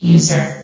S.P.L.U.R.T-Station-13 / sound / vox_fem / user.ogg
CitadelStationBot df15bbe0f0 [MIRROR] New & Fixed AI VOX Sound Files ( #6003 ) ...